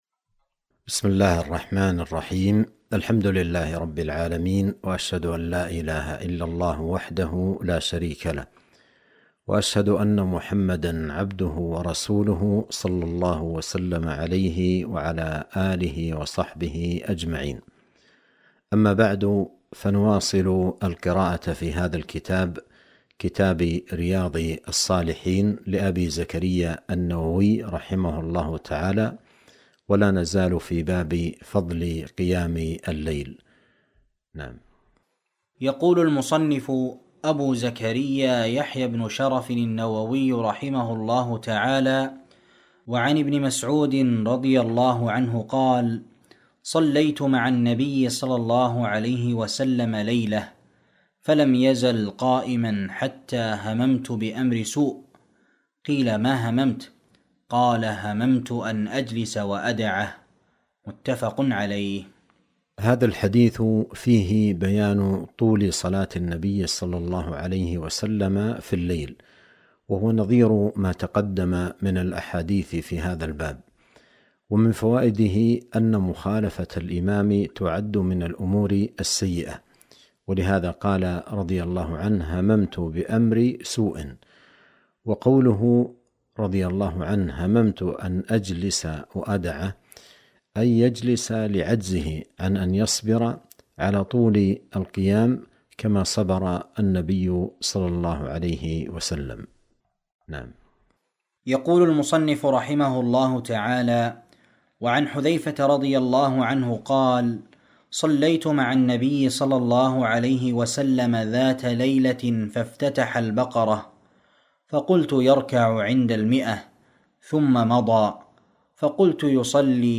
درس في قناة السنة النبوية بالمدينة النبوية